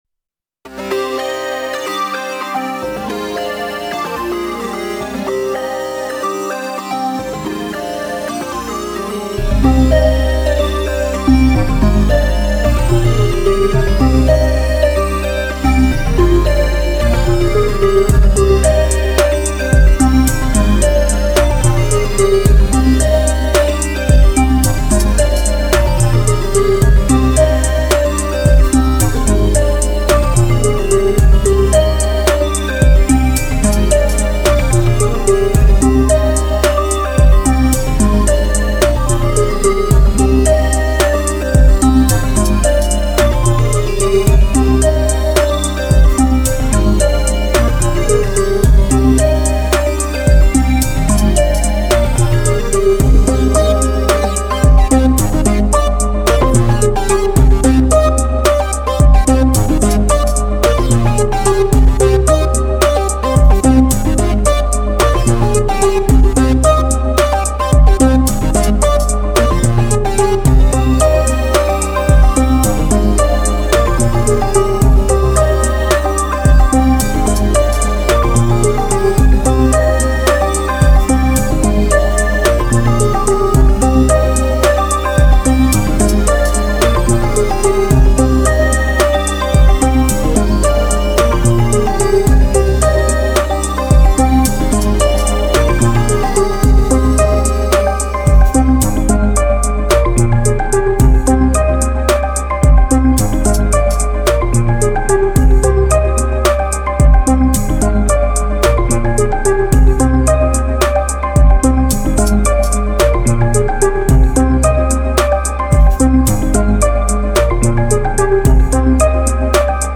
Music / Pop
pop synth dance hiphop trap techno tech house